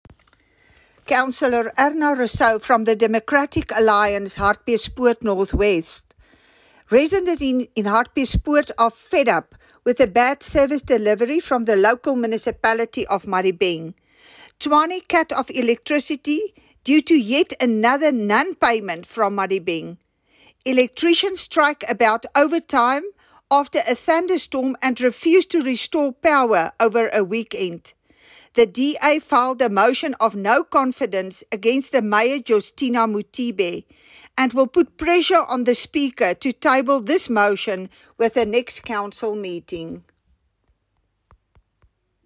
Issued by Cllr Erna Rossouw – DA Councillor: Madibeng Local Municipality
Note to Editors: Please find the attached soundbite in
English and Afrikaans by Cllr Erna Rossouw